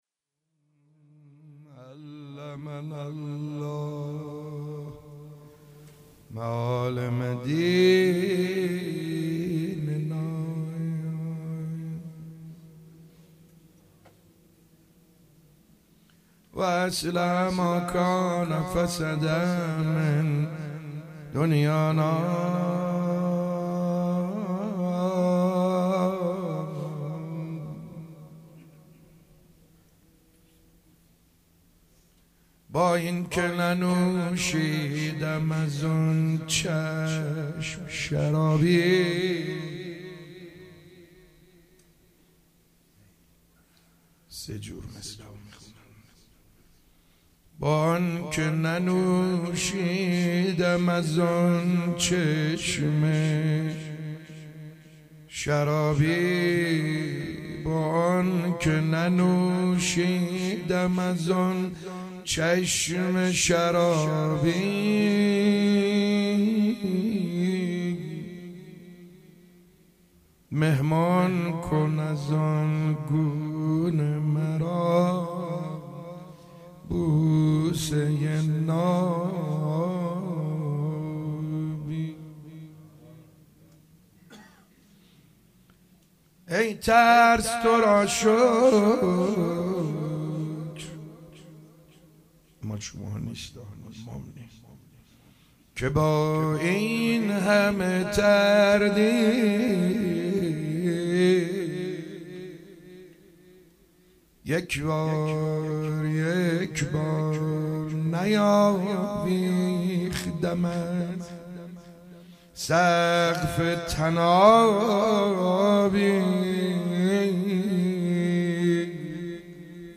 مداحی حاج سعید حدادیان شهادت امام موسی کاظم علیه السلام
مداحی های حاج سعید حدادیان در شهادت امام موسی کاظم علیه السلام (سال 1395 ه.ش)
روضه حضرت موسی بن جعفر (علیه السلام)